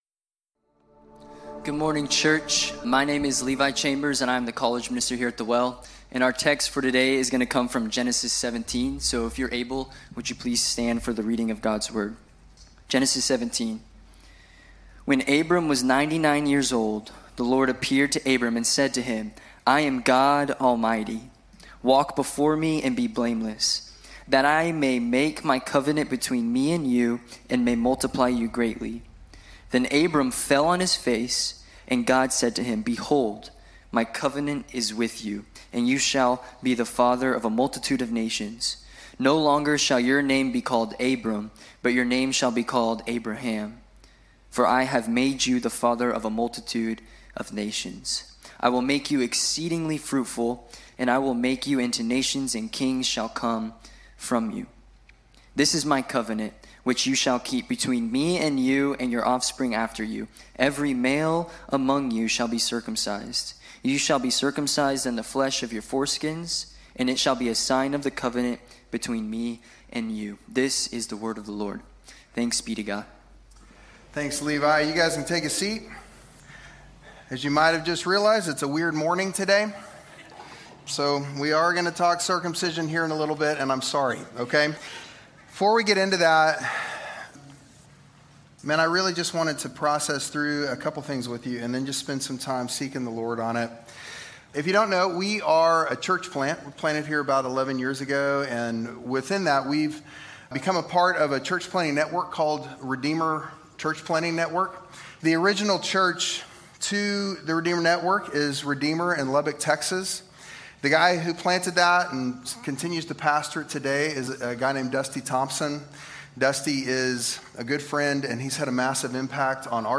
The Well Abilene Sermons